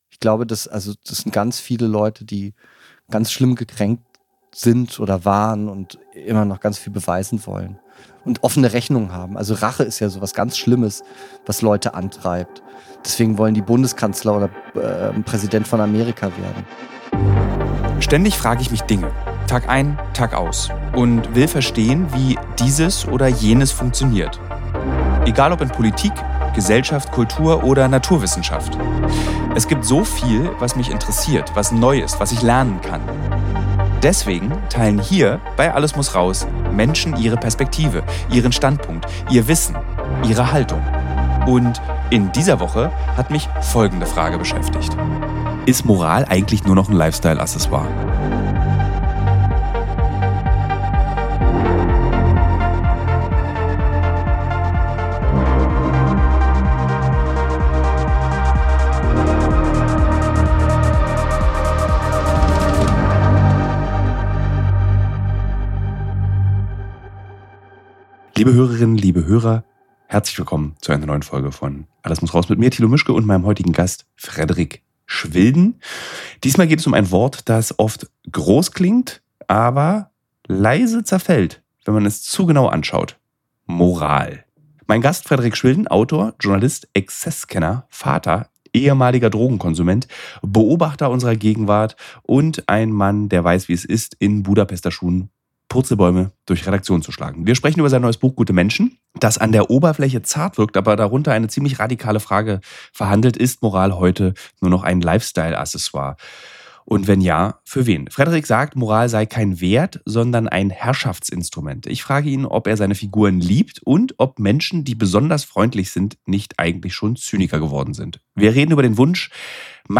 Wie versprochen läuten wir den Kultursommer ein – mit einem Gespräch